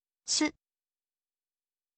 ออกเสียง: shu, ชิว, ชึ
พยัญชนะนี้ไม่มีในภาษาไทย มันใกล้กับ “ชึ” แต่เป็น “shu” ในภาษาอังกฤษ “shu” และ “chu” เป็นเสียงแตกต่างกัน เสียงนี้เป็น “ชึ” เมื่อเสียงเขียนเป็นภาษาไทย แต่มันใกล้กับ “ชิว” ตั้งใจฟังเสียงและเลียนแบบกันเถอะ